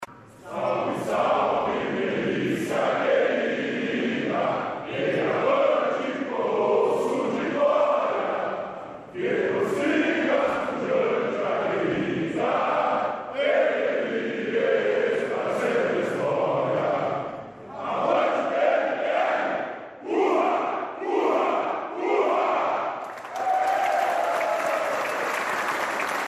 Depois de aprovação do Plano de Carreira dos Praças, categoria canta hino.
Ouça o hino da PM, a Canção do  10 de Agosto, que os policias cantaram para celebrar a aprovação do projeto de lei que instituiu o Plano de Carreira dos Praças, durante a sessão plenária desta segunda-feira (2).